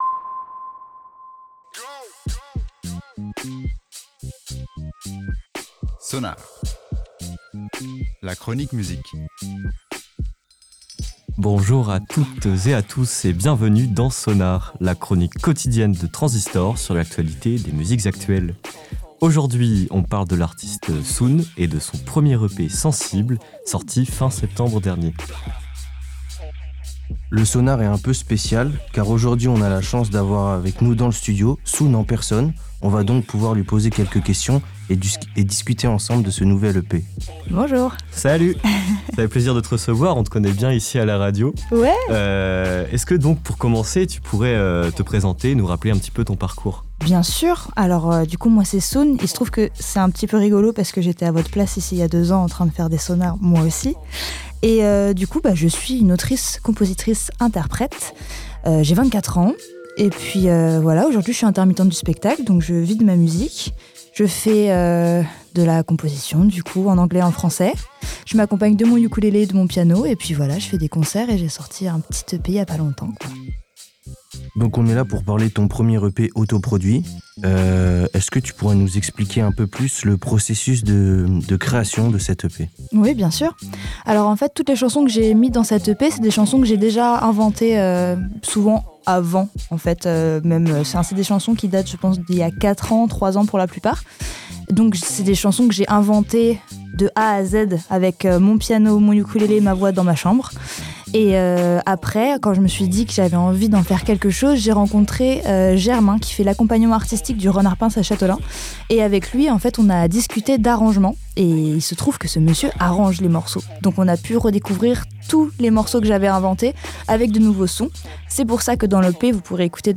(Interview)